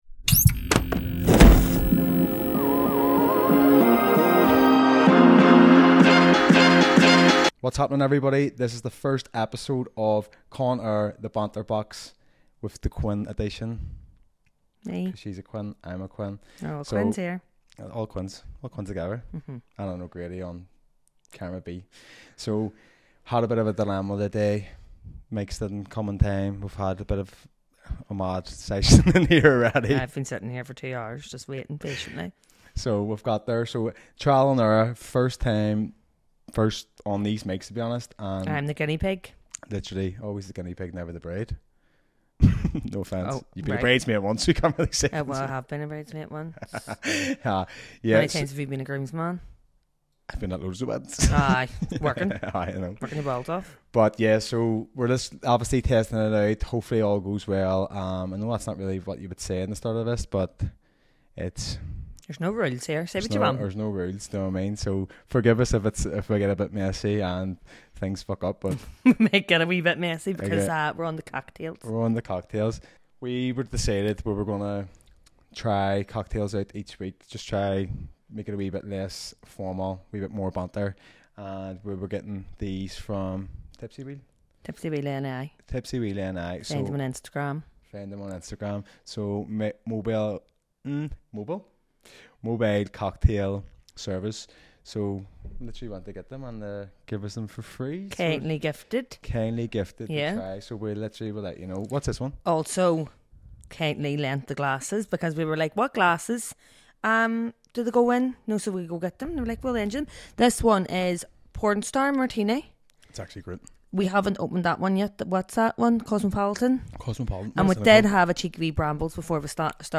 In this debut episode, I reflect on what I've learned so far - like my tendency to say "like" a lot, talk at lightning speed, and occasionally mash up sentences.
We've kept our promise and delivered plenty of banter, laughs, and giggles.